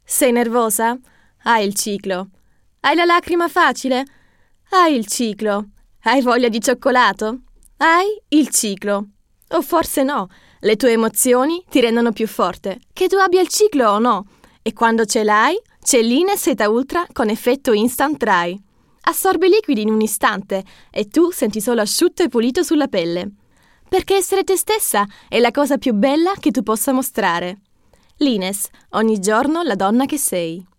Meine Stimme – klar, warm, wandelbar.
In meinem eigenen Studio entstehen hochwertige Aufnahmen – effizient, professionell und mit viel Herz für das gesprochene Wort.
Werbung – ITALIENISCH – (dynamisch) 🇮🇹
Werbung-ITALIENISCH-dynamisch.mp3